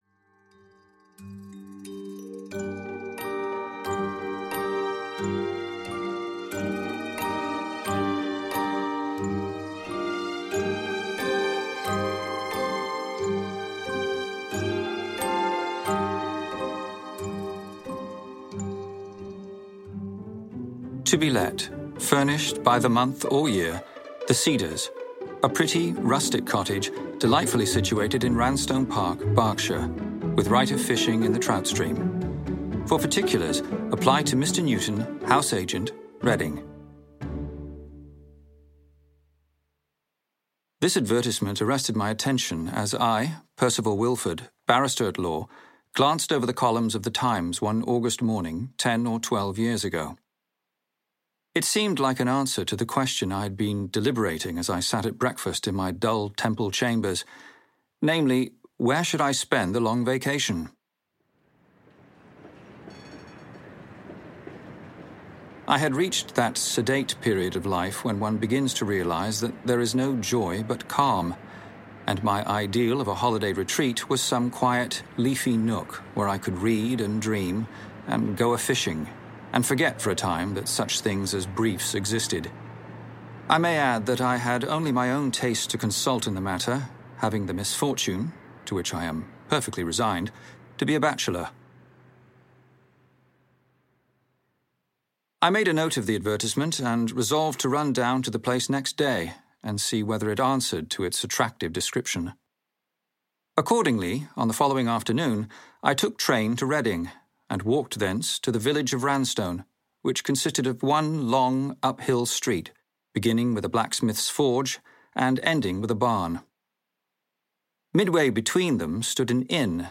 Audio knihaLove from the Dark Side
Ukázka z knihy